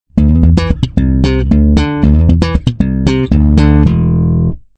Bajo eléctrico tocado con slap
El slap es una técnica más percusiva y llamativa.
• Thumb: golpear la cuerda con el pulgar.
• Pop: tirar de la cuerda y soltarla para que golpee contra el diapasón.
bajo-tocado-con-slap.mp3